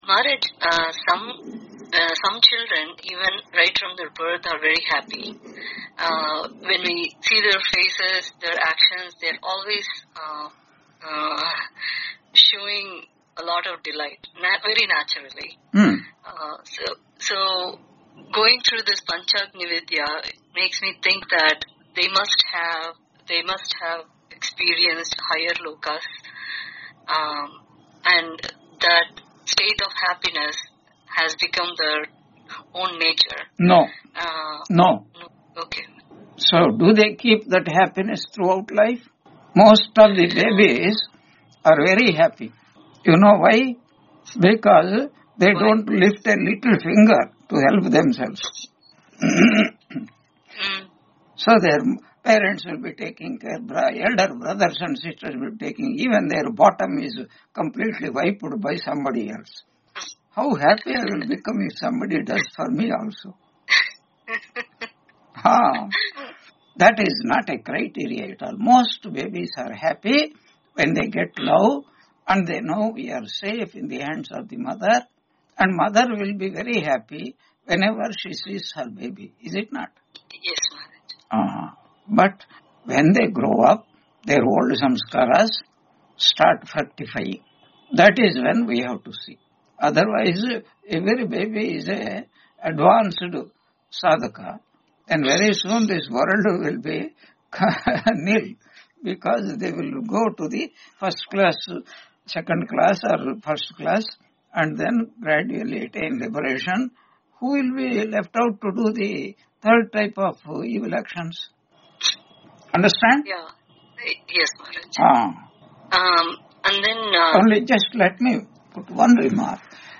Chandogya Upanishad 5.10.7-10 Lecture 171 on 10 January 2026 Q&A - Wiki Vedanta